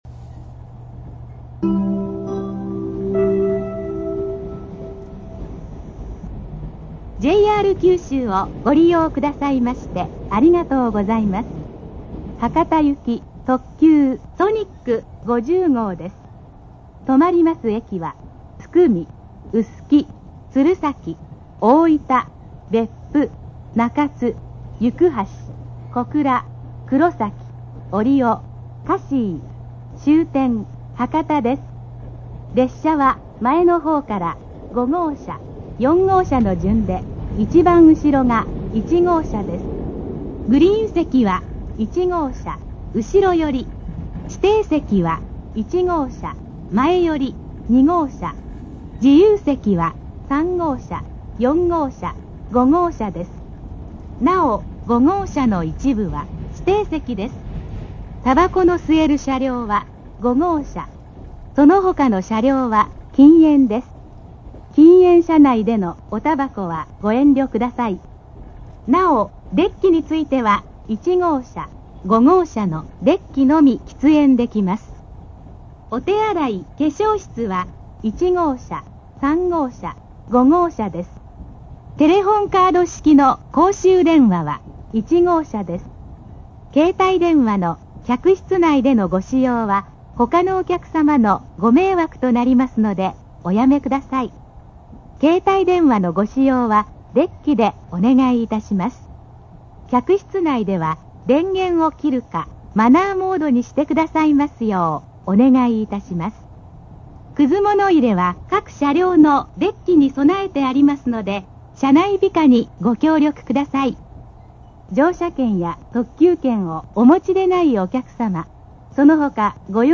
佐伯発車後に収録しました。始発駅発車後と言うことで「停車駅」「車内設備」など、一通り細かく放送されています。　英語放送付きです。